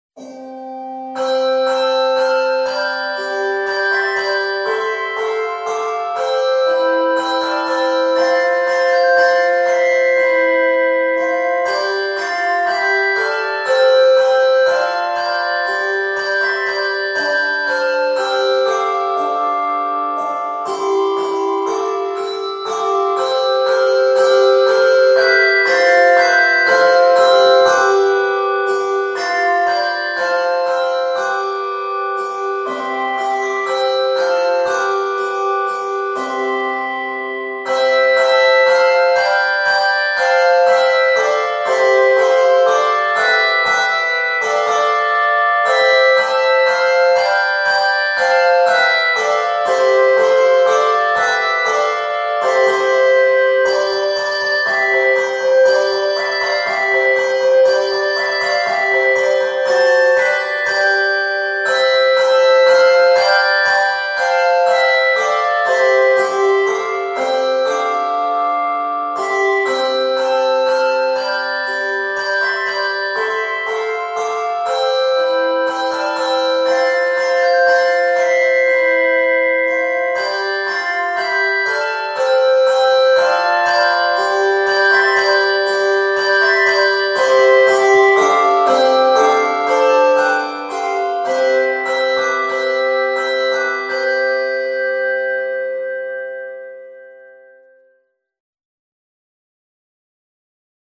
quartet arrangement
Key of C Major.